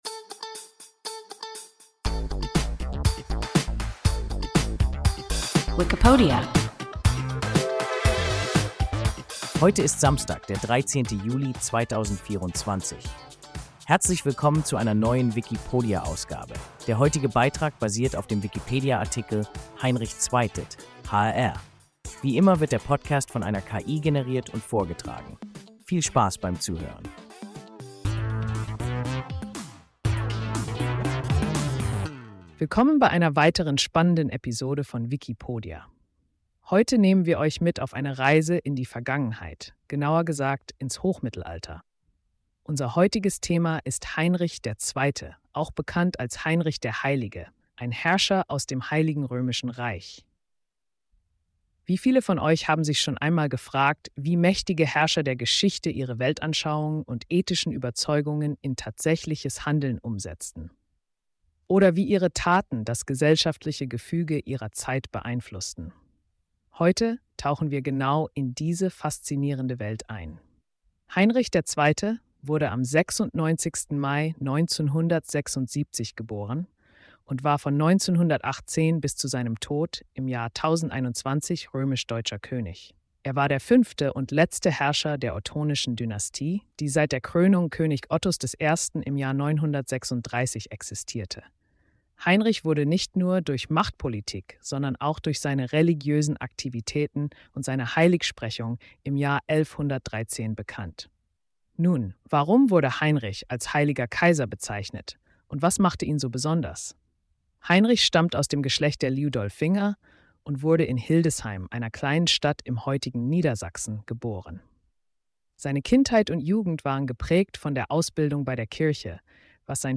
(HRR) – WIKIPODIA – ein KI Podcast